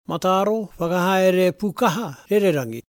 ngāti porou